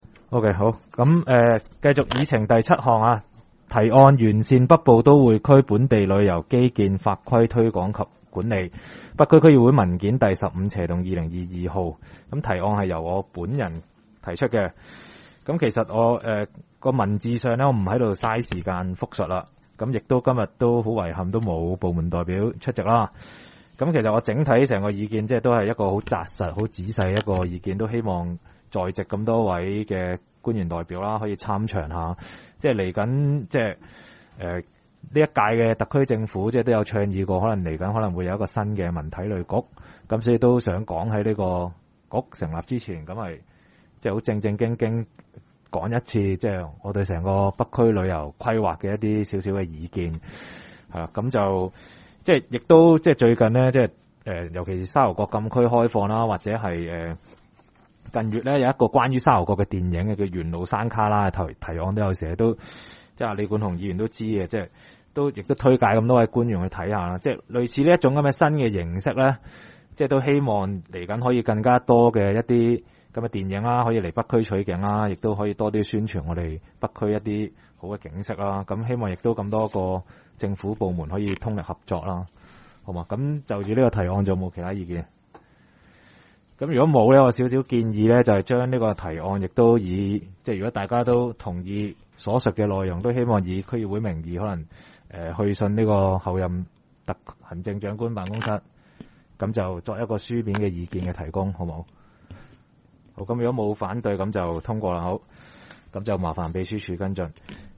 区议会大会的录音记录
北区区议会第十一次会议
北区民政事务处会议室